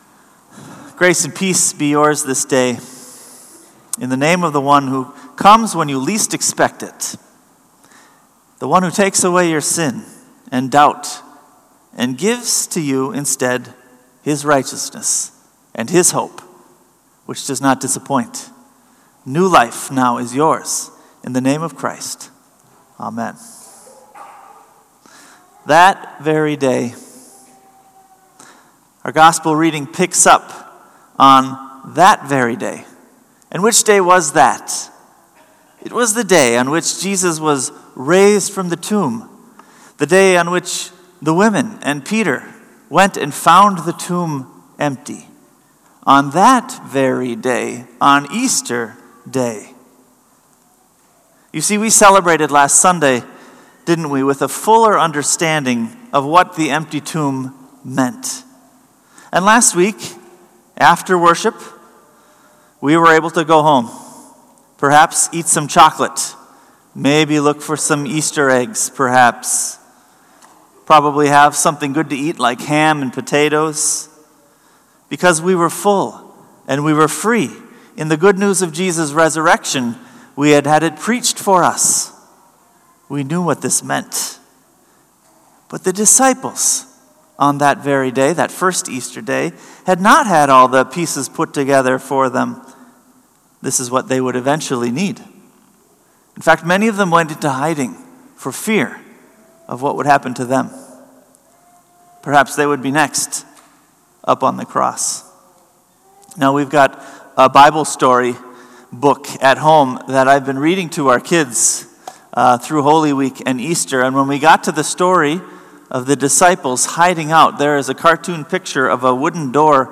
Sermon “Stay A Little Longer”